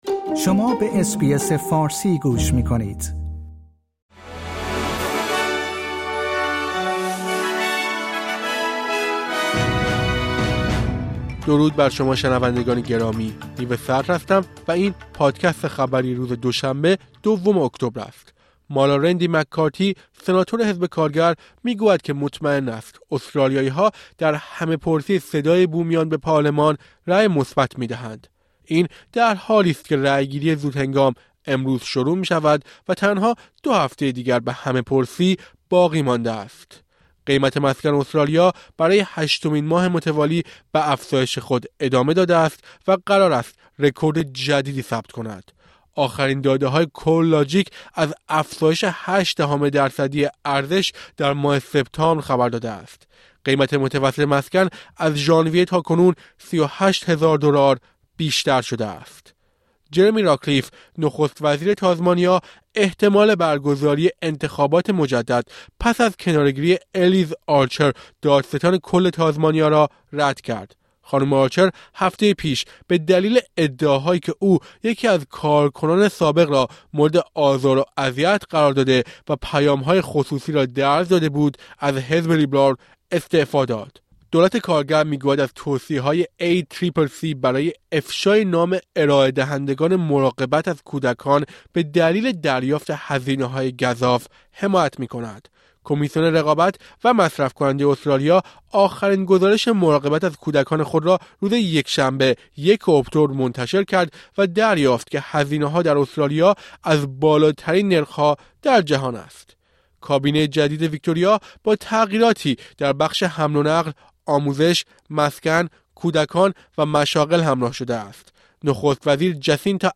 در این پادکست خبری مهمترین اخبار استرالیا و جهان در روز دوشنبه ۲ اکتبر، ۲۰۲۳ ارائه شده است.